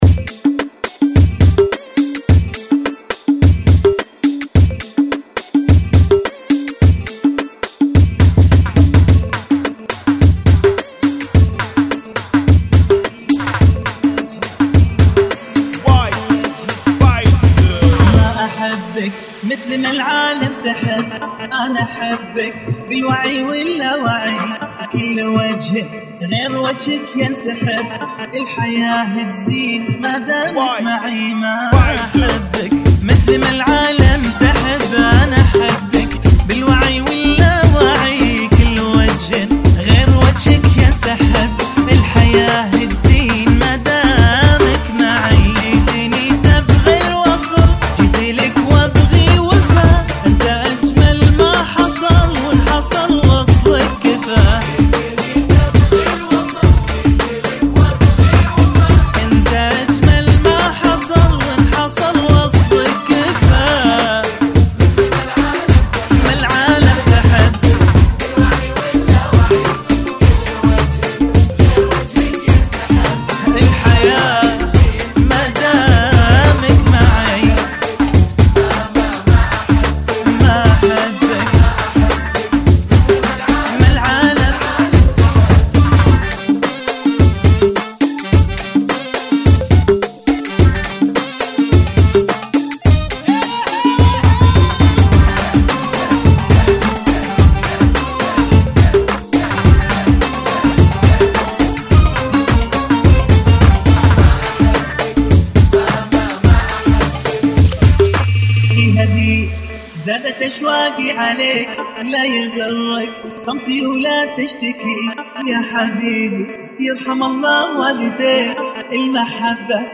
Funky [ 106 Bpm ]